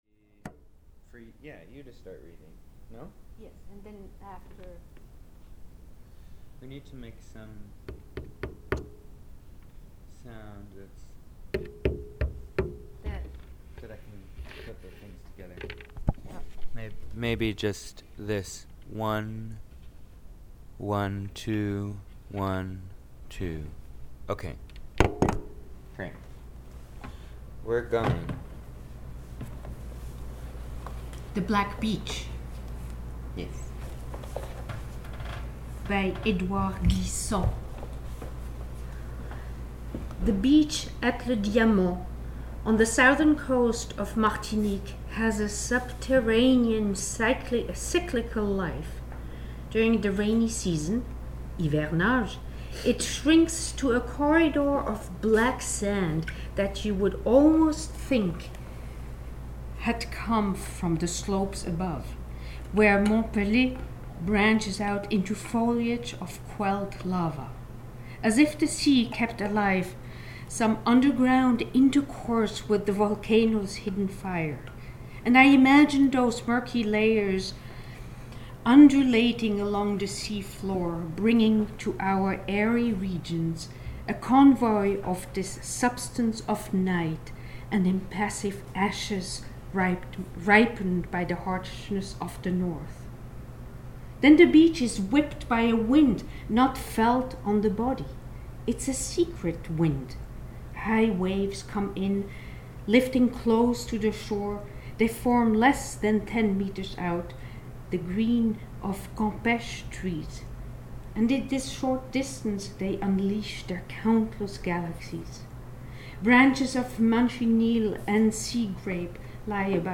First broadcast on Montez Press Radio